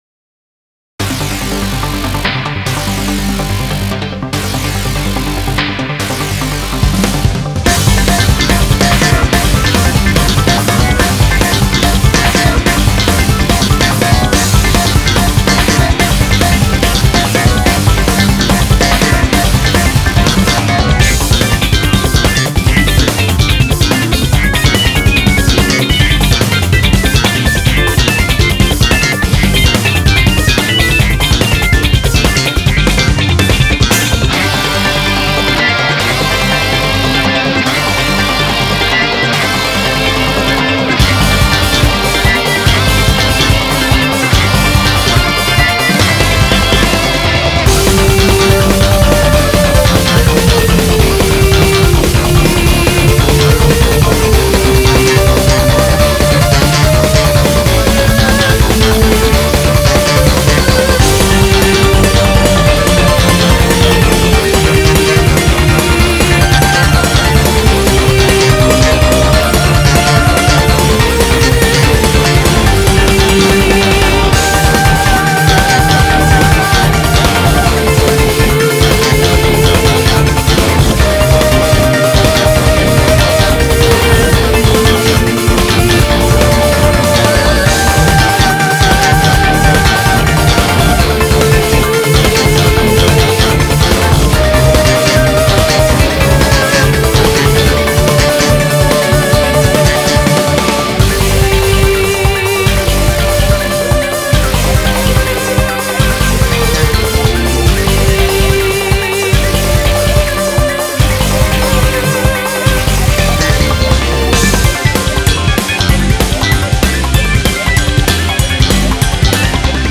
BPM144